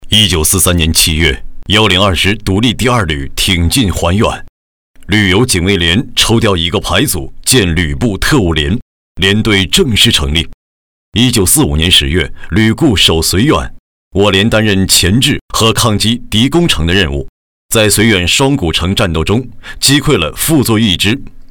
稳重磁性 企业专题,人物专题,医疗专题,学校专题,产品解说,警示教育,规划总结配音
大气磁性、稳重。质感男音。擅长科技风宣传片、记录片解说、旁白题材。